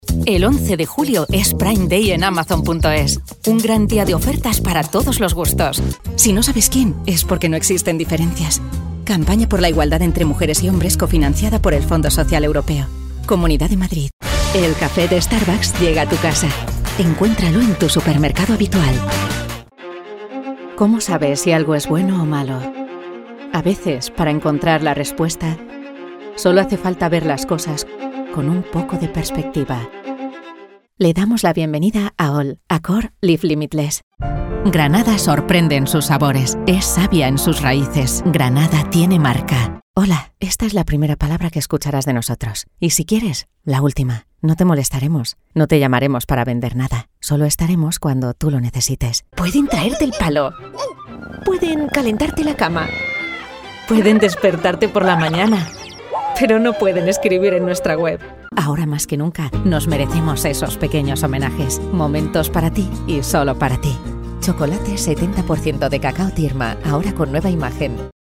Female
Spanish - Spain (Castilian)
Narration Without Music
Different Registers Of My Voice